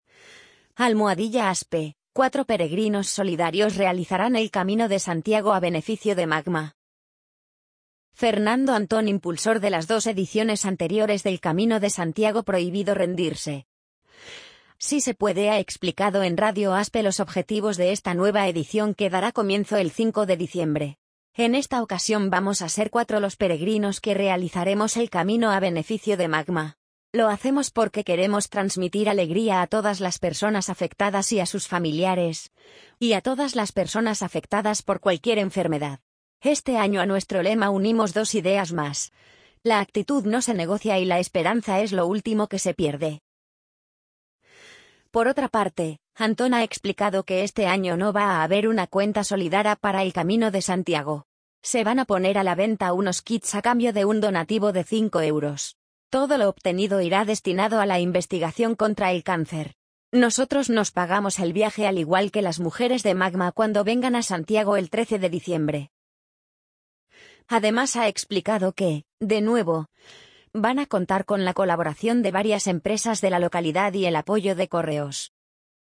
amazon_polly_38335.mp3